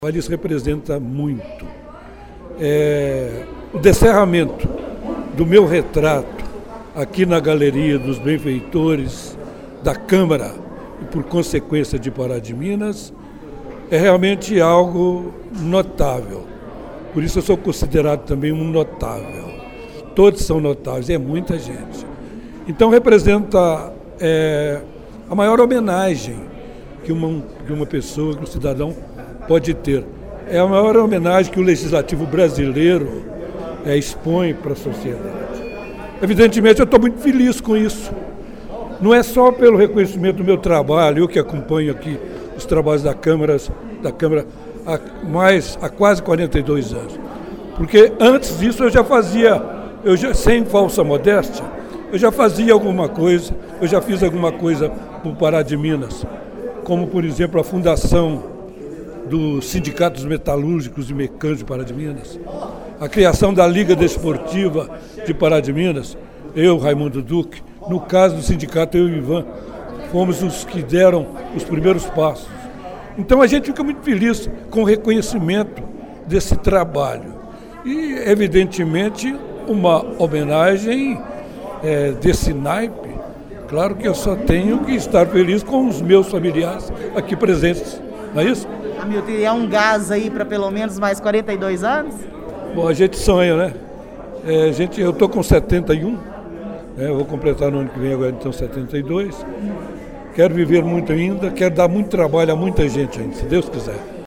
Assim foi a quinta-feira (09) na Câmara de Vereadores de Pará de Minas, que realizou uma sessão solene para entrega de Moções de Aplausos e afixação de retratos na Galeria dos Benfeitores de Pará de Minas e também na Galeria de Vereadoras.